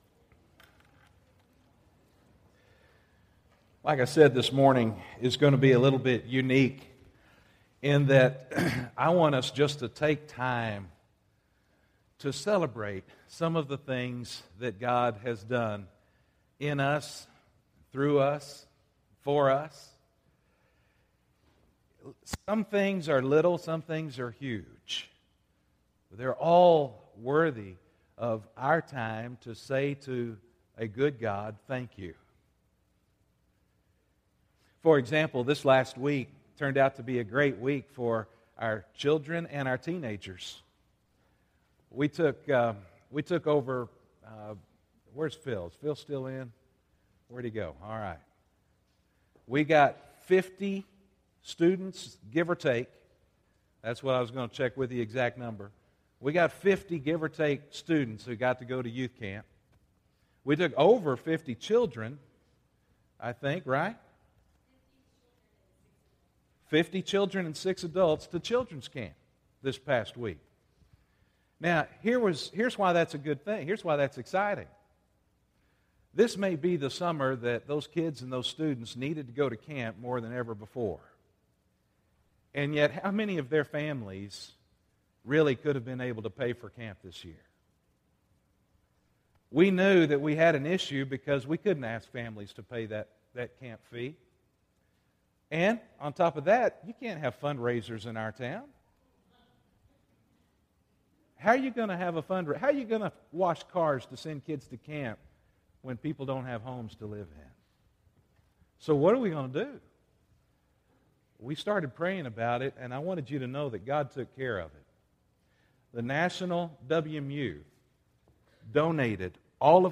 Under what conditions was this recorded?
This is not really a sermon, but it was a special worship time of giving thinks to God for all the ways He has blessed us during this time of recovery.